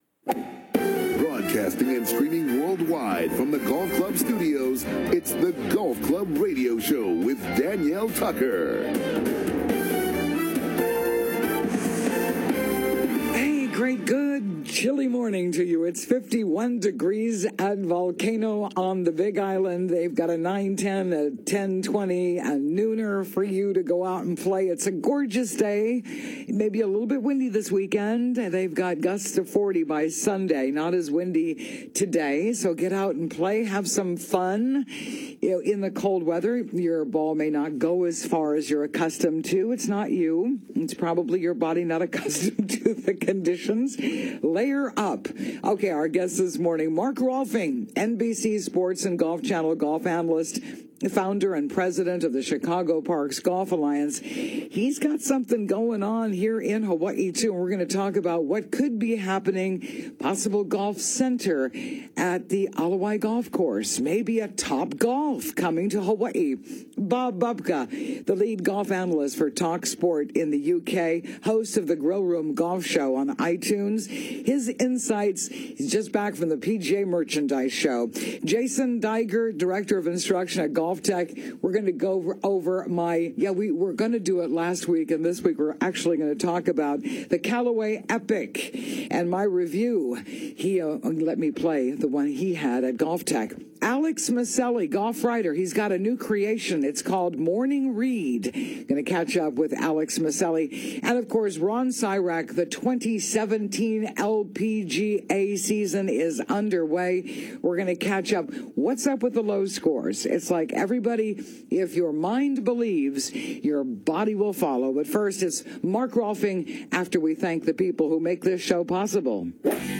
In The Clubhouse Mark Rolfing: NBC and Golf Channel Analyst